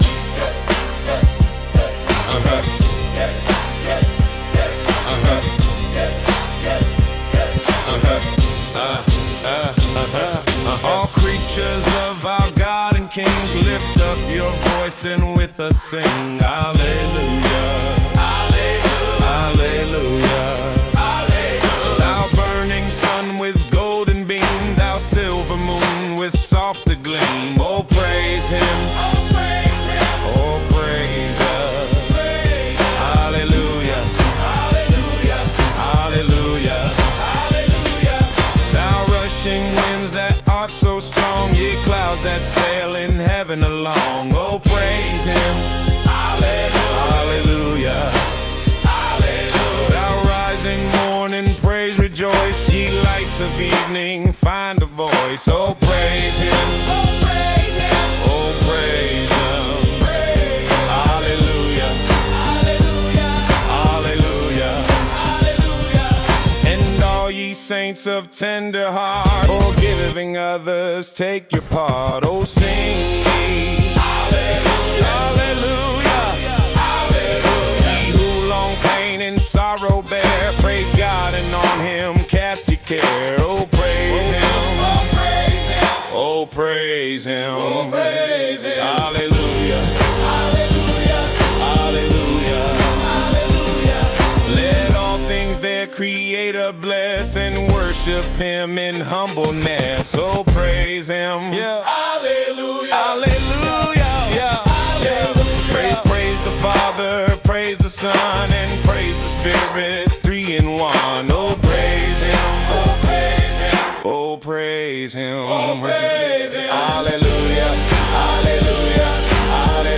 Rap version of the traditional hymn